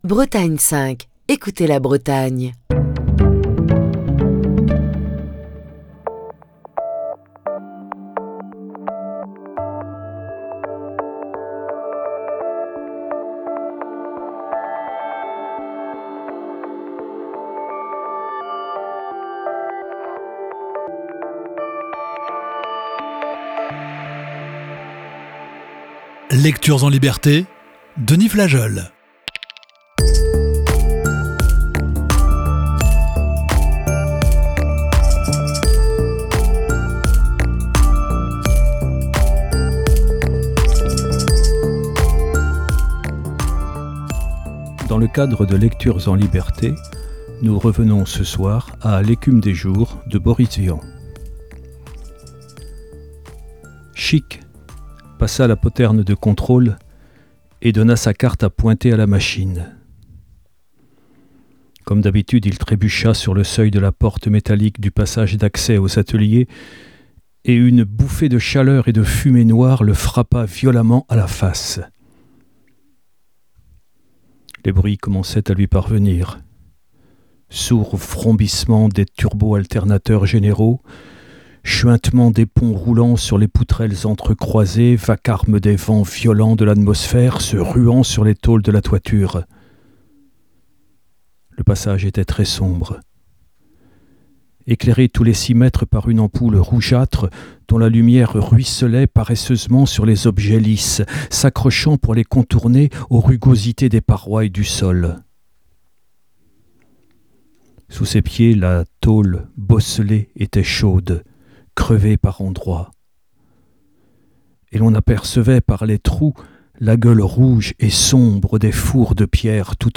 Émission du 18 septembre 2023.